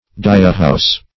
Dyehouse \Dye"house`\, n. A building in which dyeing is carried on.